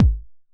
TR 909 Kick 02.wav